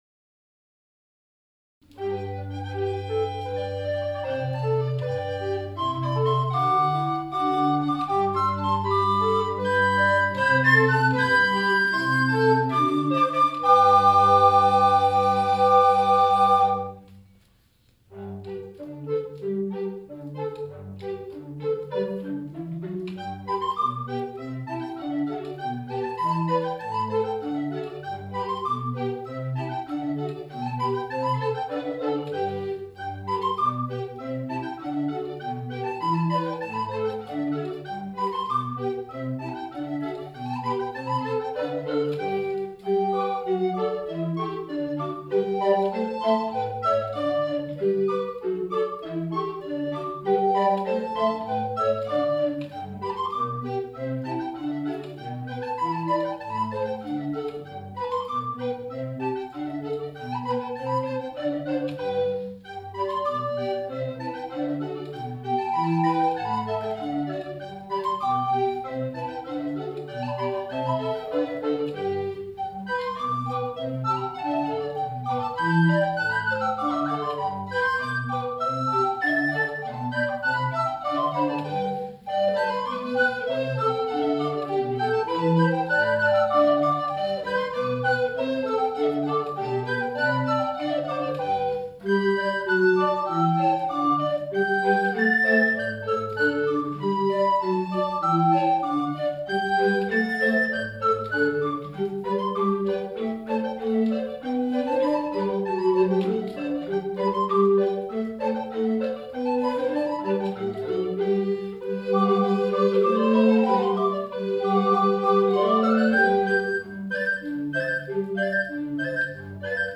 VOL. ２7a　東大阪市民美術センター　ロビーコンサート　2011